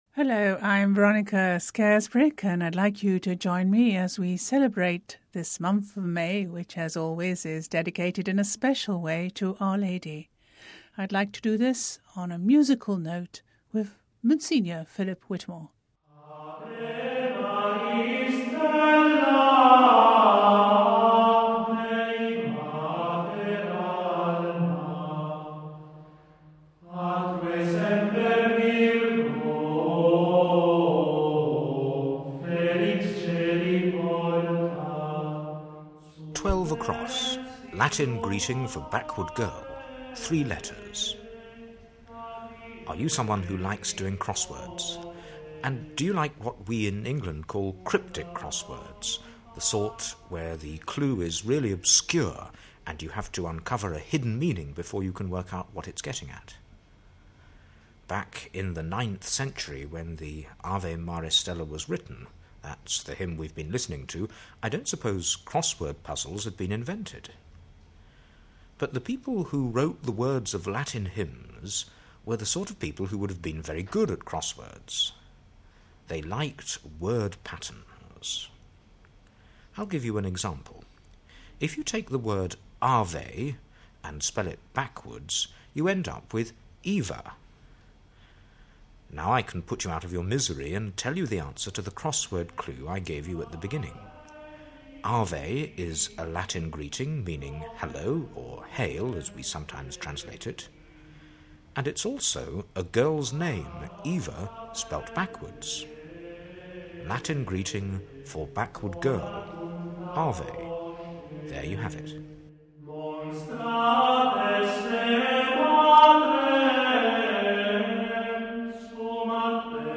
sumptous arrangement of the haunting plainsong 'Ave Maris Stella